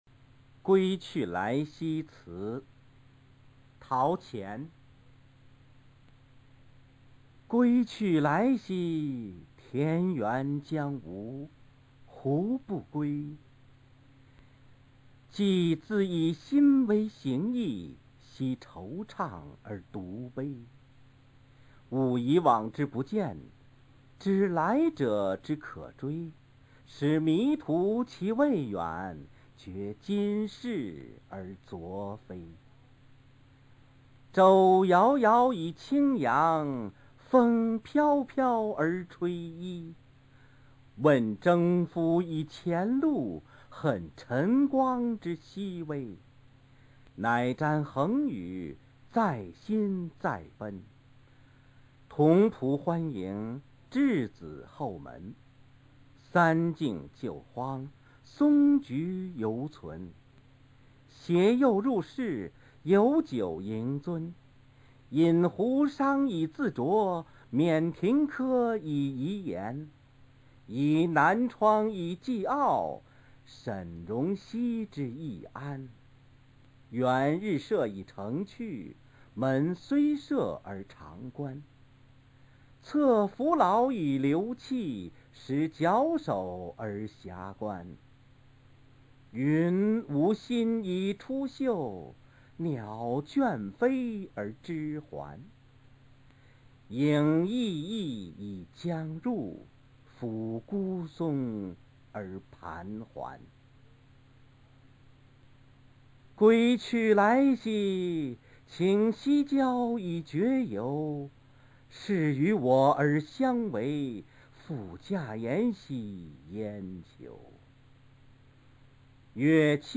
归去来兮辞朗读